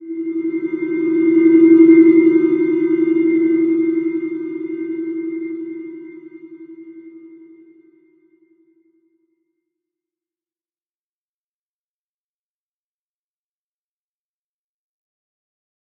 Slow-Distant-Chime-E4-mf.wav